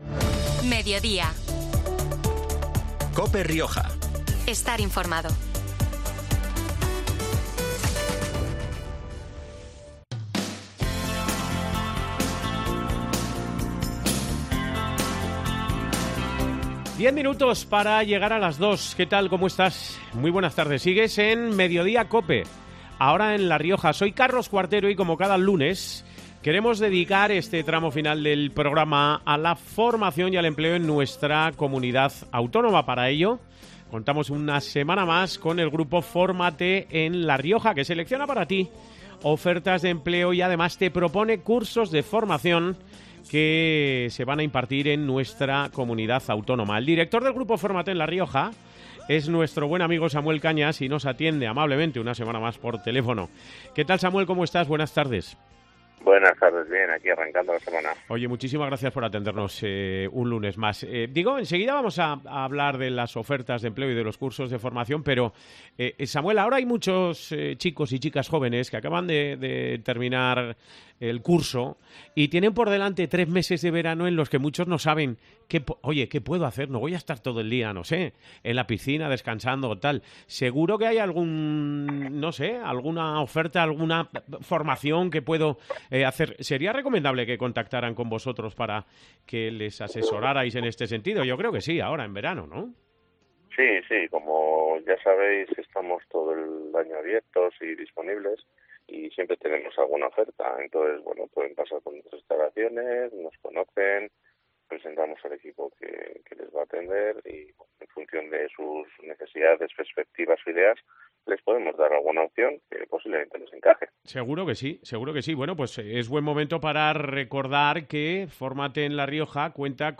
Como cada lunes hemos dedicado una sección del programa regional a la formación y al empleo en nuestra Comunidad, porque el grupo 'Fórmate en La Rioja' selecciona para tí varias ofertas de empleo y te propone cursos de formación que se van a impartir en la Comunidad en los próximos días, semanas o incluso meses.